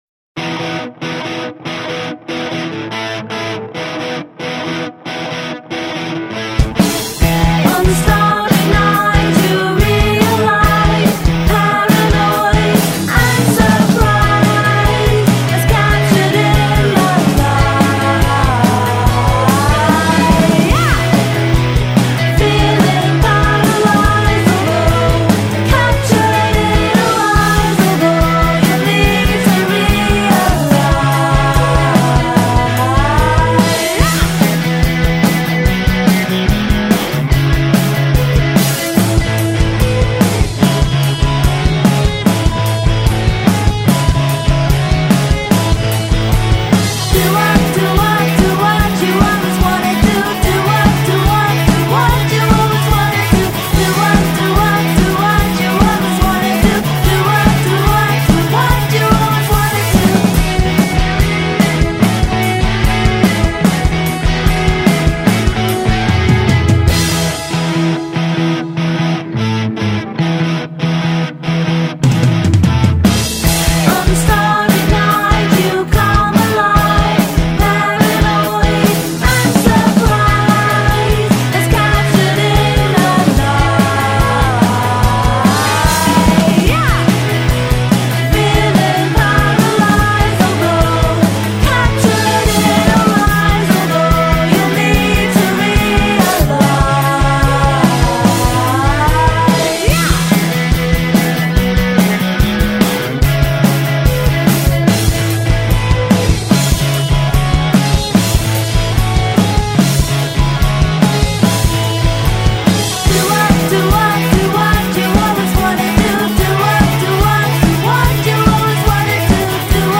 power-pop five piece